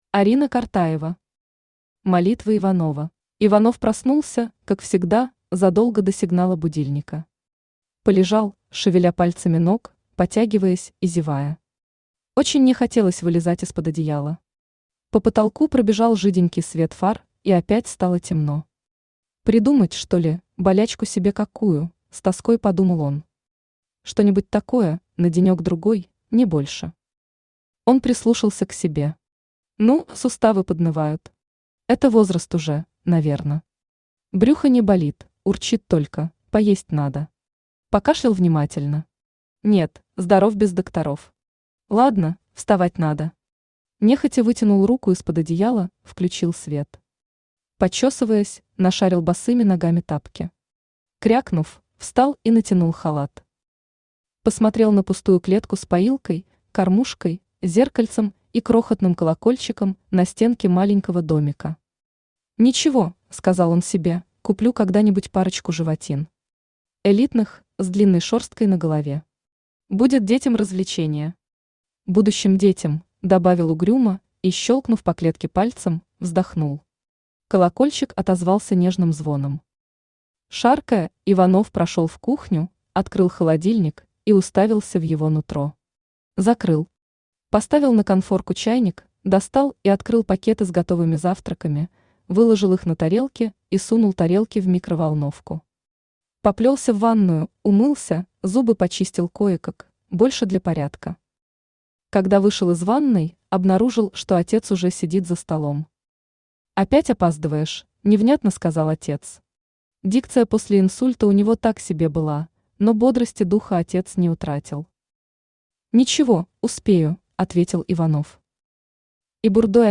Аудиокнига Молитва Иванова | Библиотека аудиокниг
Aудиокнига Молитва Иванова Автор Орина Ивановна Картаева Читает аудиокнигу Авточтец ЛитРес.